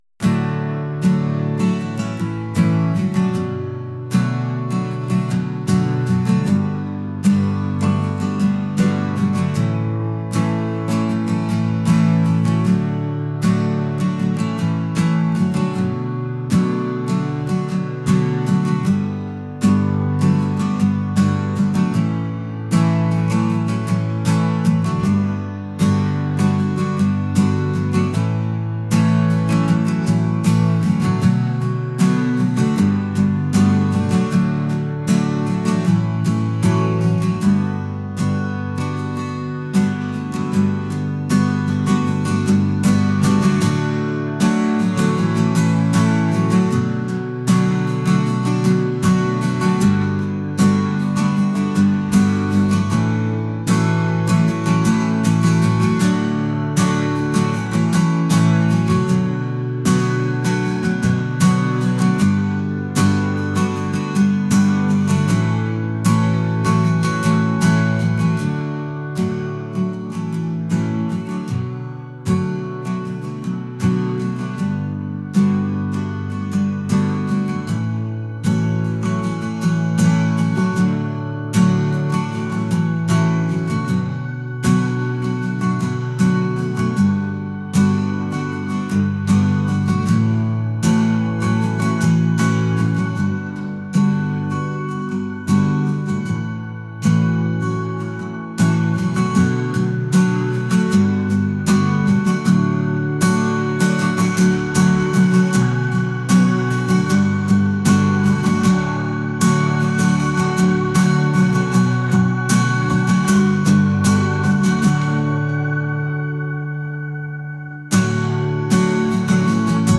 acoustic | indie | folk